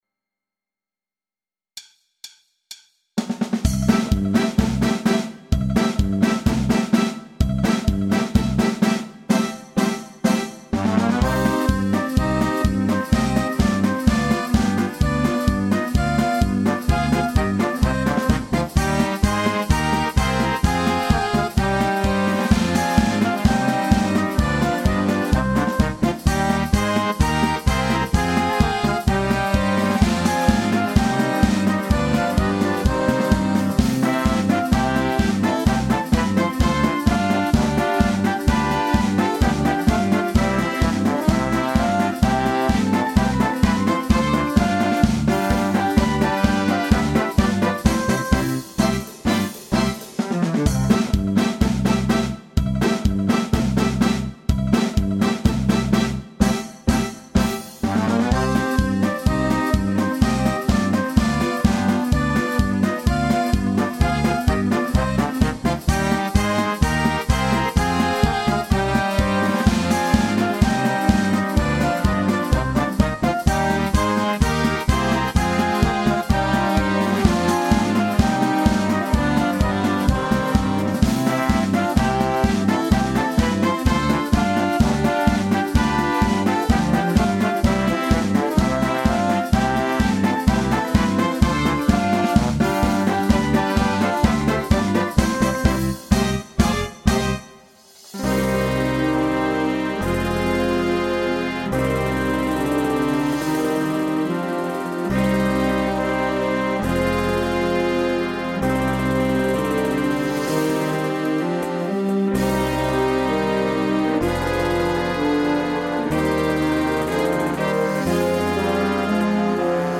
Tango-Bolero-Polka Dance